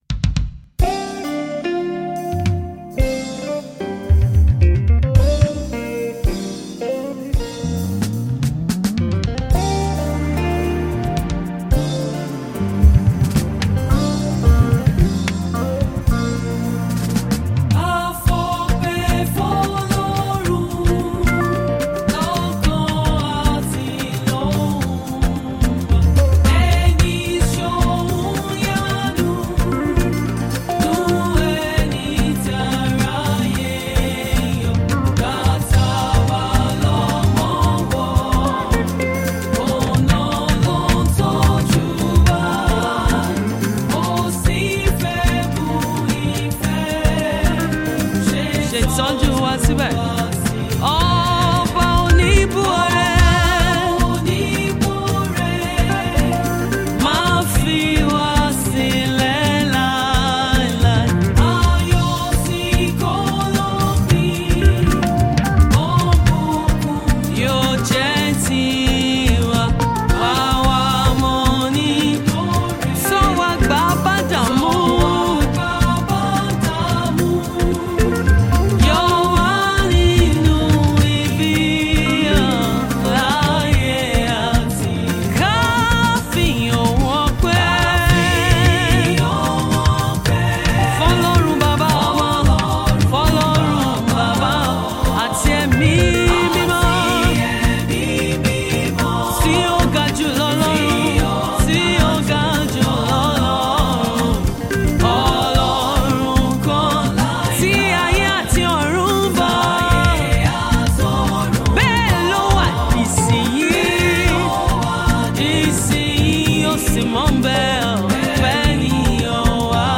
Fuji Music
Gospel musician